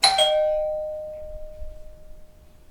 dingdong2
bell door house sound effect free sound royalty free Sound Effects